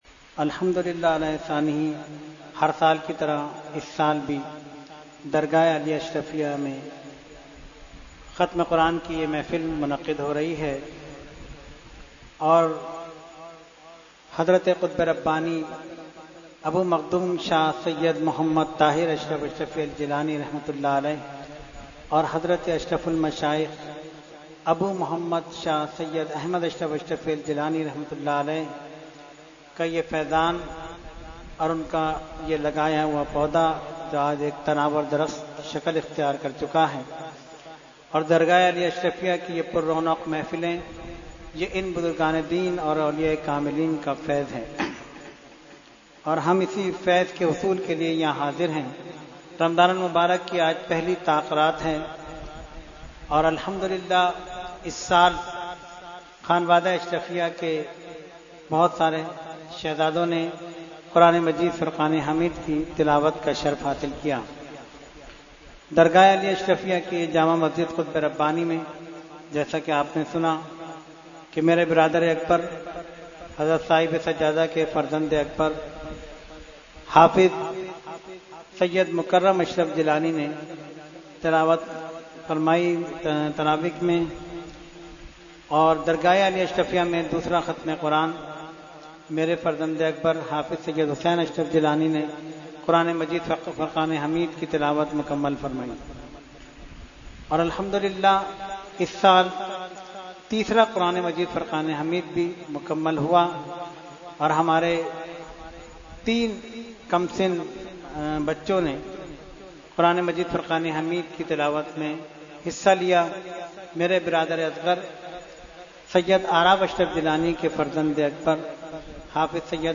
Short Speech – Khatmul Quran 2018 – Dargah Alia Ashrafia Karachi Pakistan